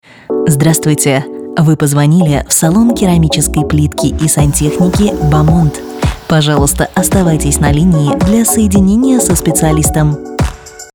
GP-Bomond-s-muzykoj.mp3